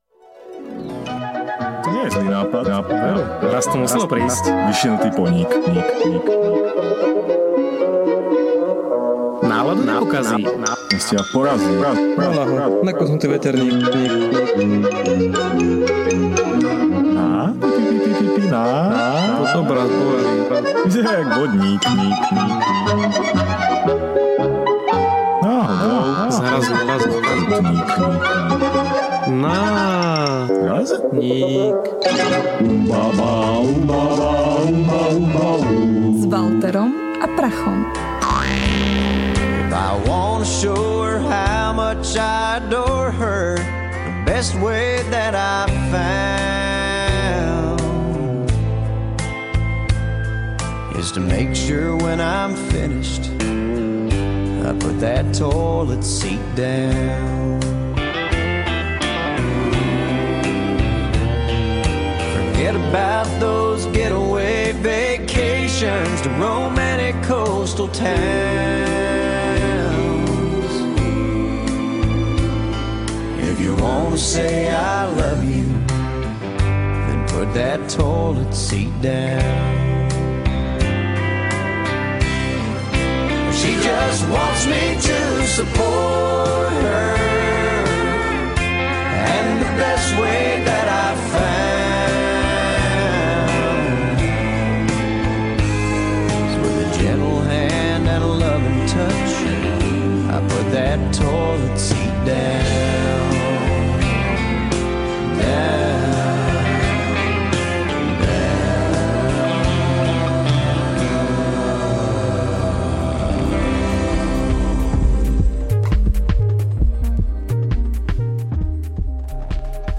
Ukecaná štvrtková relácia rádia TLIS NÁRAZNÍK #25